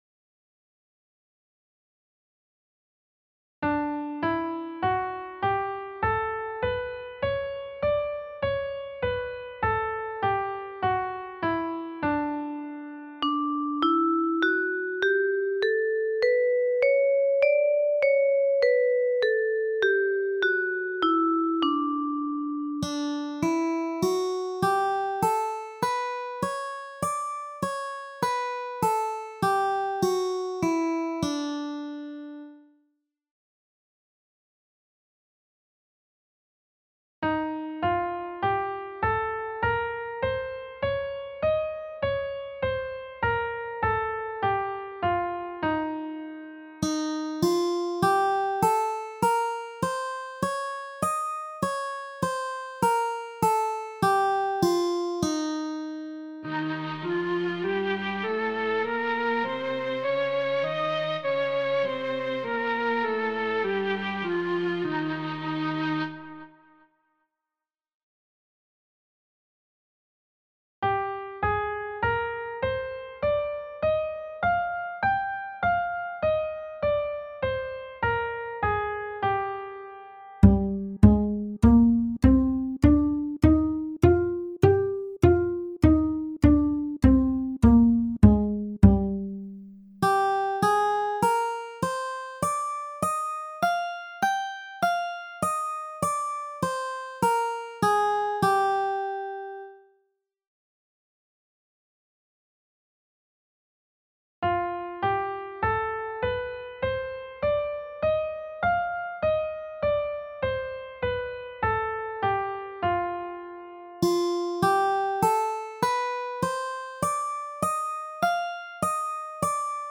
Riconoscimento e identificazione delle scale modali relative a quelle maggiori e minori